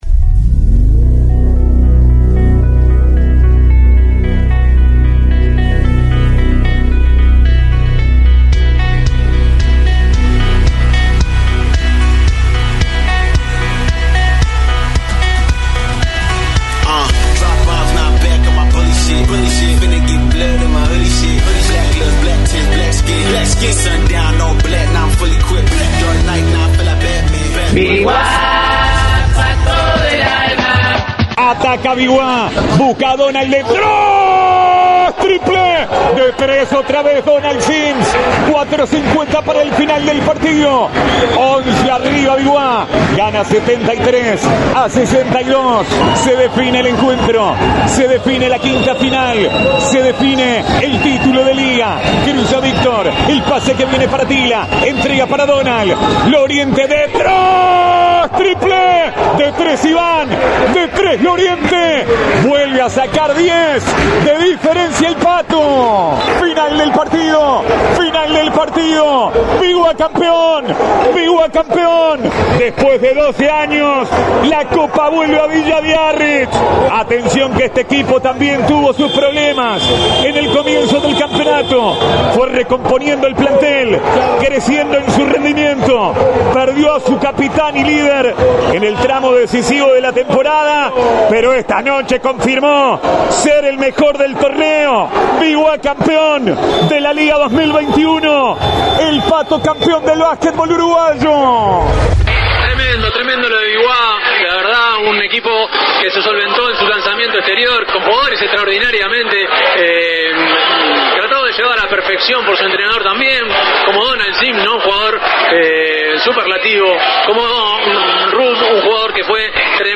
Biguá se consagró campeón de la Liga Uruguaya 2021 tras derrotar a Nacional en la serie final 3-2. Reviví el programa especial de Pica la Naranja con los jugadores y dirigentes campeones.